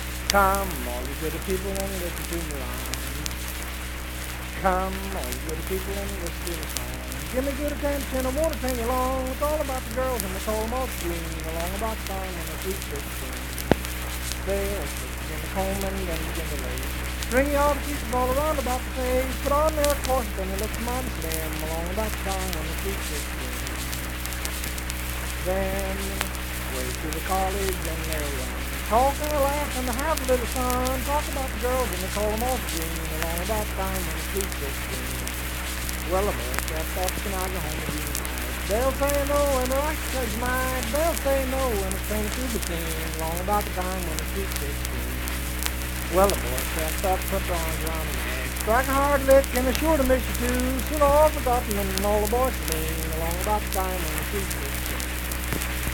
Unaccompanied vocal music
Voice (sung)
Saint Marys (W. Va.), Pleasants County (W. Va.)